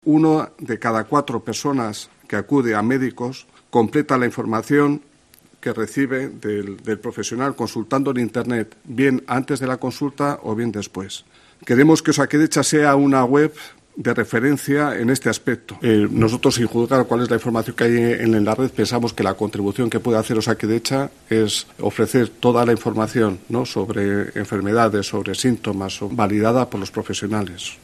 Juan Luis Diego, director general de Osakidetza